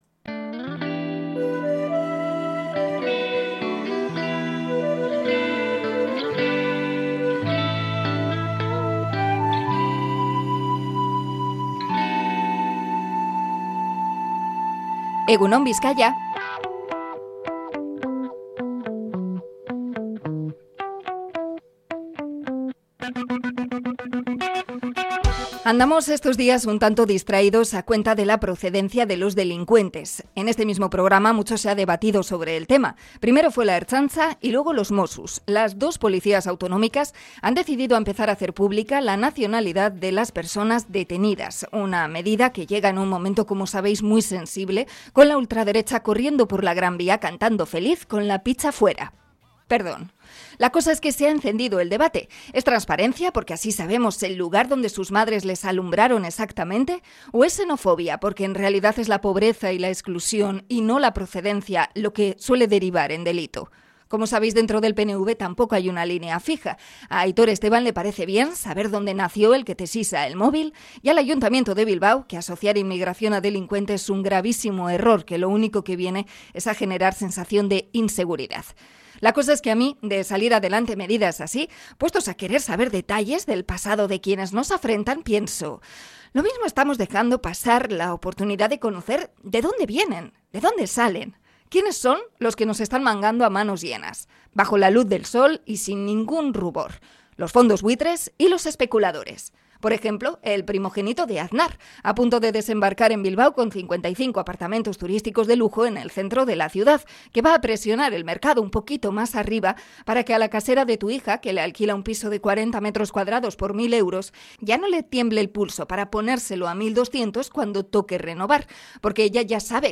Comentario sobre la procedencia de los delincuentes en Bilbao